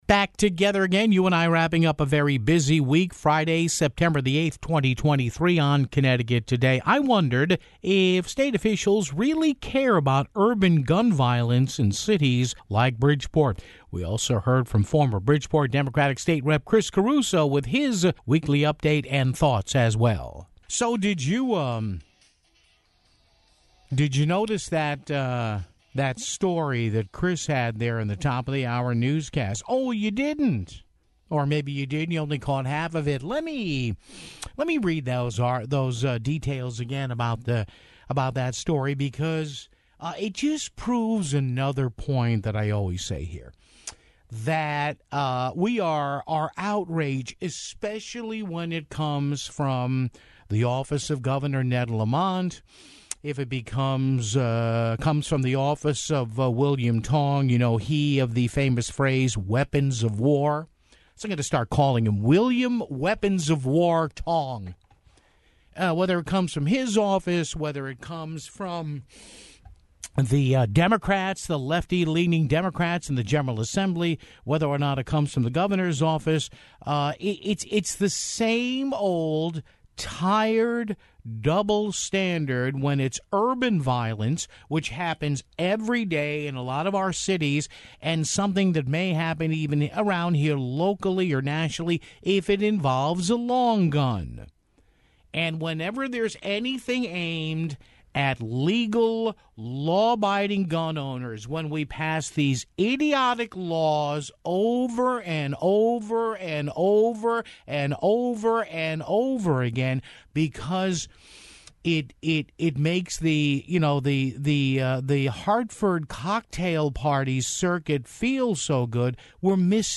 Wealso heard from former Bridgeport Democratic State Rep. Chris Caruso with his weekly update (11:46)